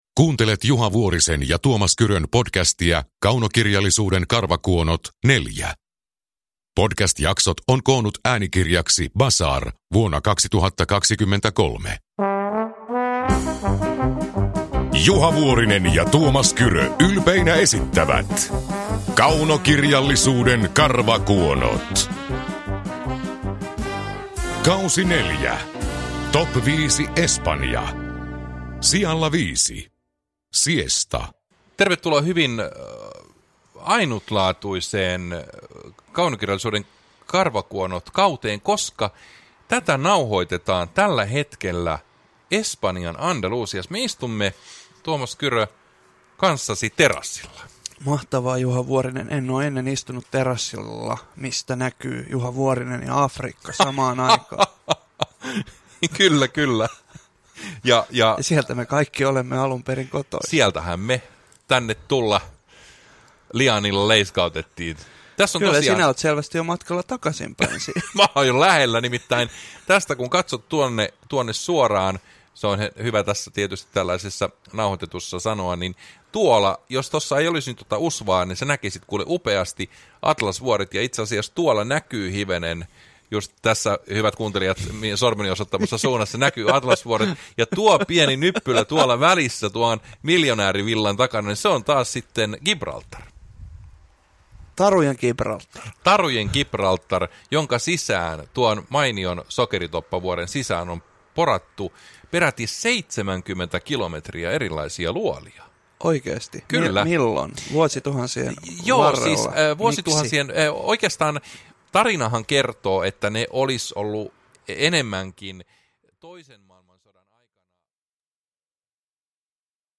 Kaunokirjallisuuden karvakuonot K4 – Ljudbok
Uppläsare: Tuomas Kyrö, Juha Vuorinen